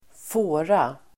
Uttal: [²f'å:ra]